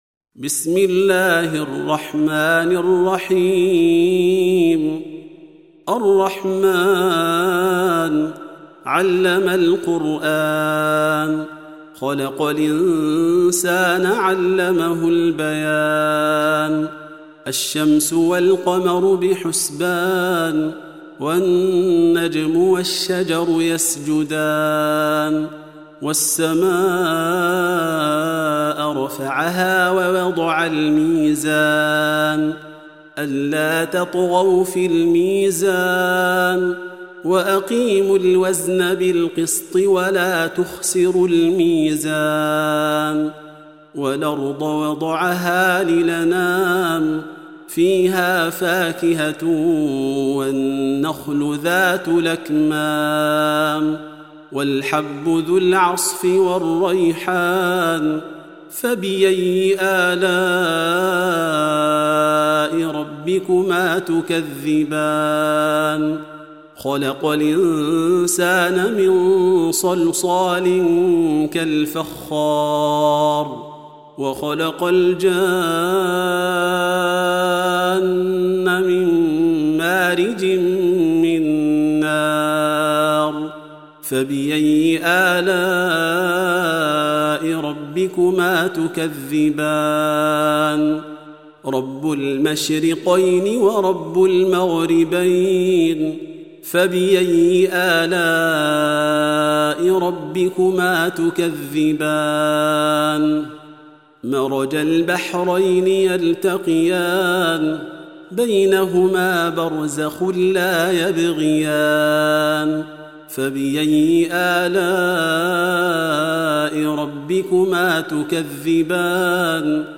Surah Sequence تتابع السورة Download Surah حمّل السورة Reciting Murattalah Audio for 55. Surah Ar-Rahm�n سورة الرحمن N.B *Surah Includes Al-Basmalah Reciters Sequents تتابع التلاوات Reciters Repeats تكرار التلاوات